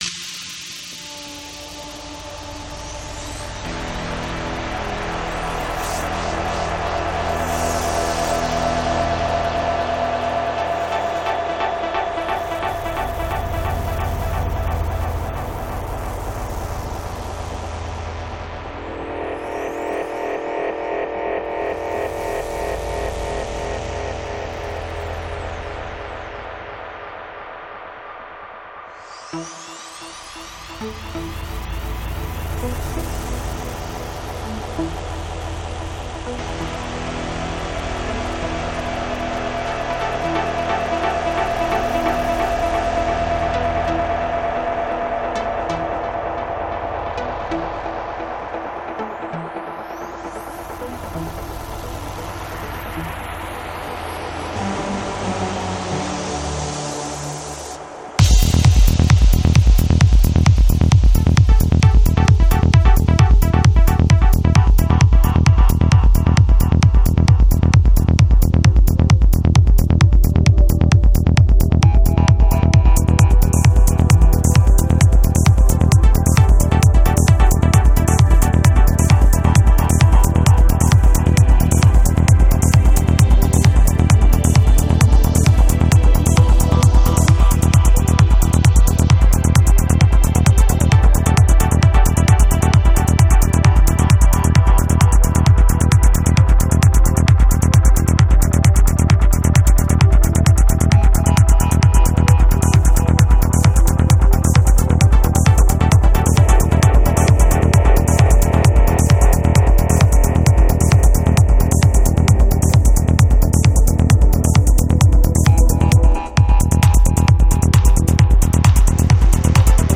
Жанр: Psychedelic